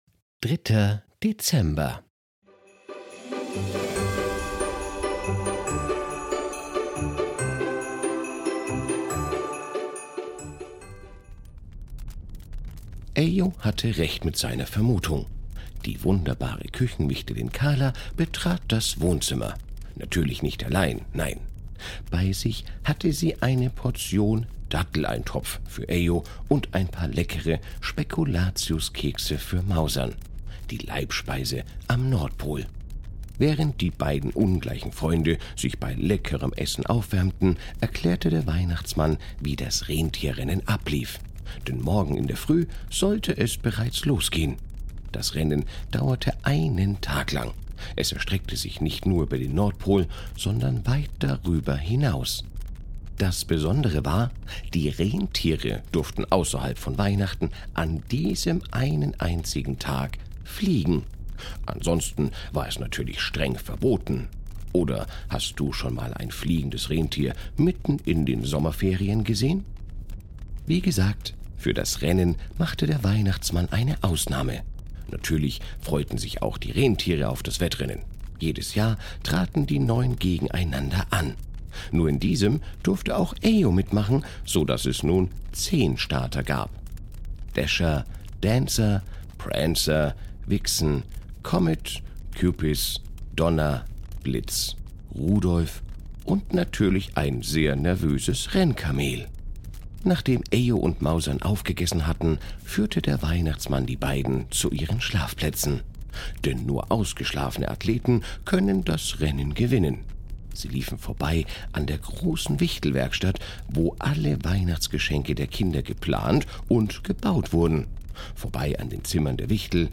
Ein Kinder Hörspiel Adventskalender